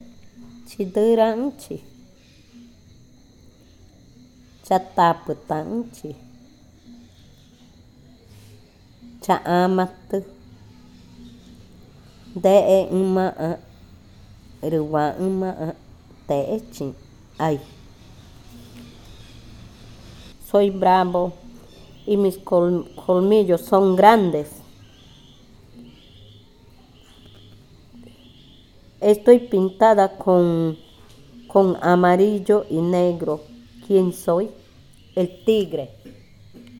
Adivinanza 26. El jaguar
Cushillococha